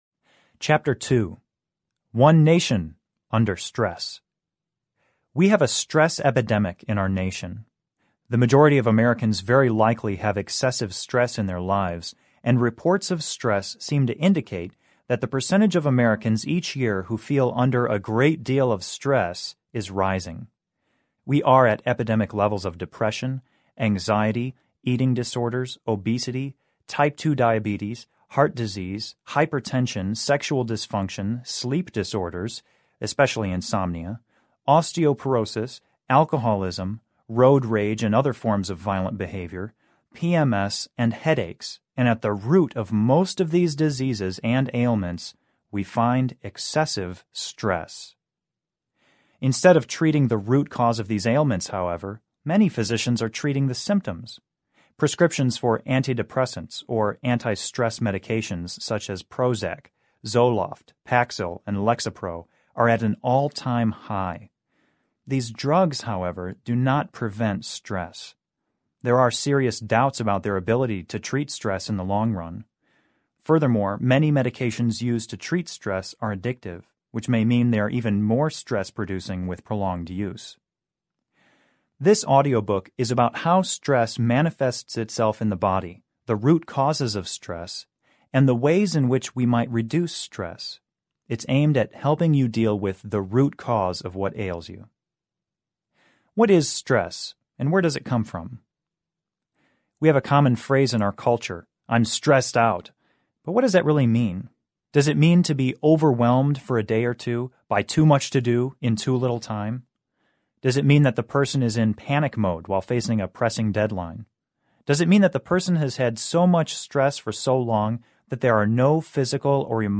Stress Less Audiobook